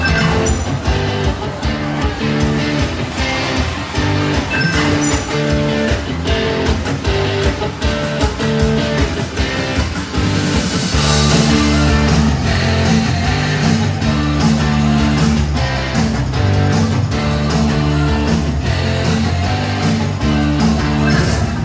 在跳舞机上听到的点歌界面背景音乐.mp3 (170.06 Kb)